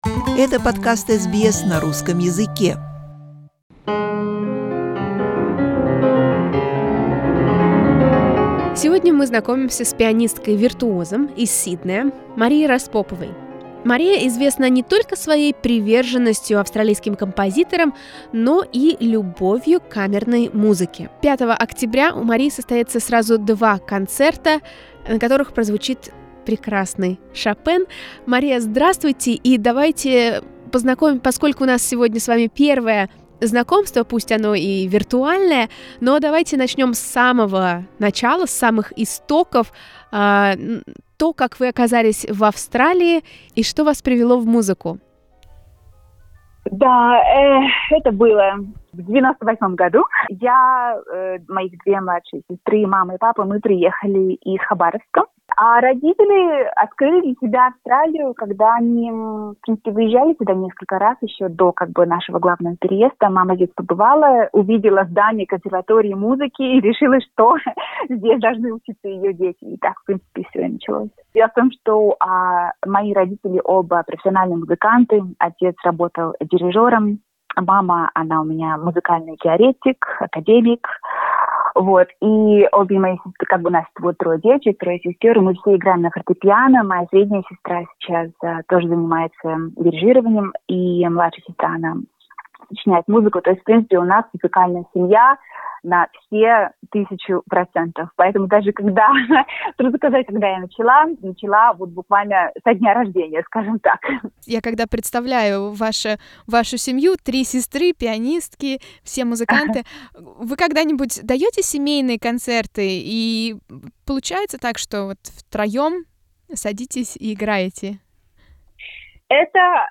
We speak with before her recital in Sydney Opera house.